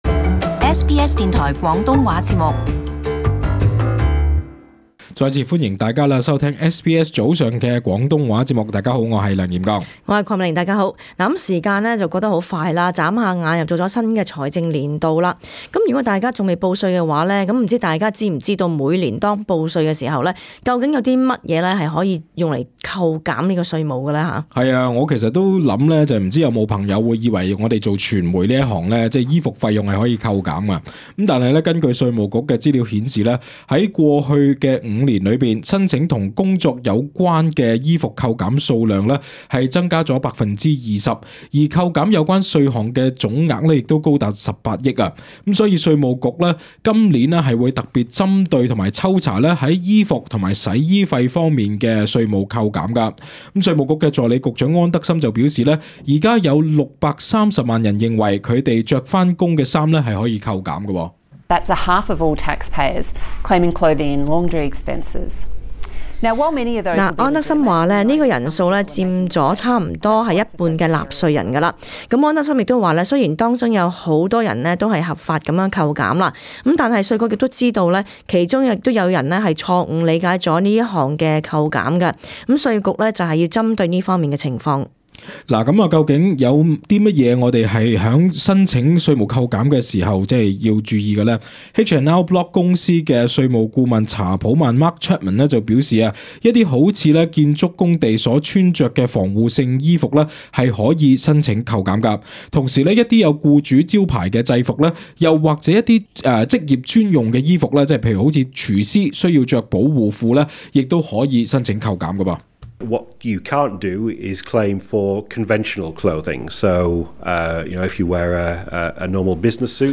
【時事報導】甚麼可以扣稅